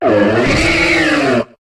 Cri de Blindépique dans Pokémon HOME.